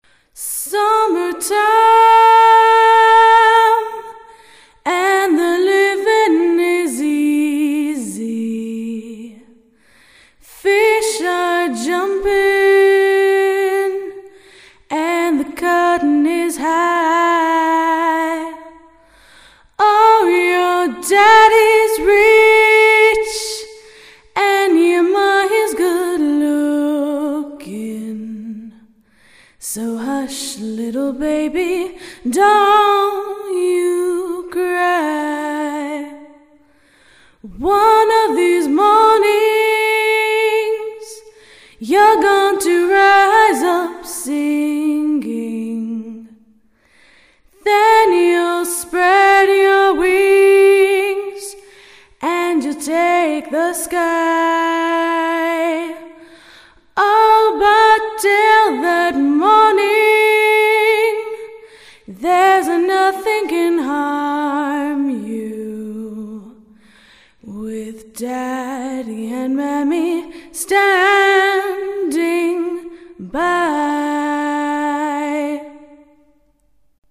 House DJ